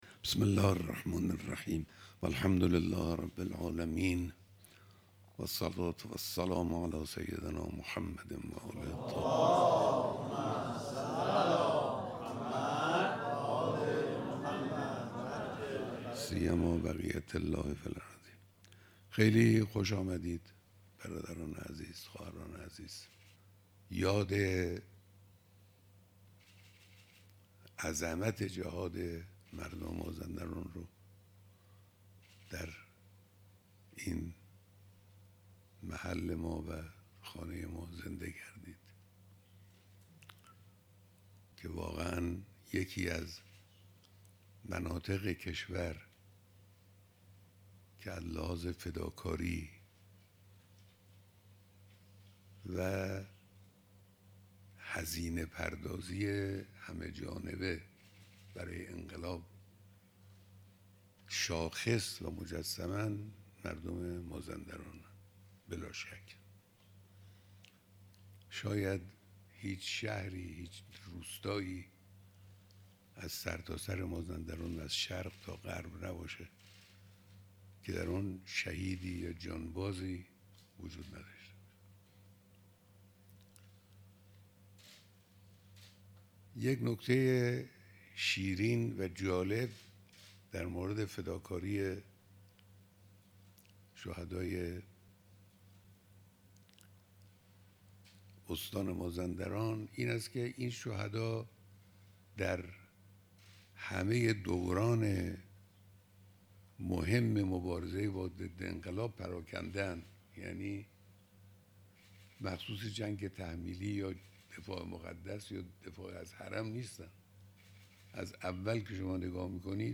بیانات در دیدار دست اندرکاران برگزاری کنگره بزرگداشت 14 هزار و 500 شهید استان مازندران